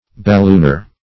Search Result for " ballooner" : The Collaborative International Dictionary of English v.0.48: Ballooner \Bal*loon"er\, n. One who goes up in a balloon; an a["e]ronaut.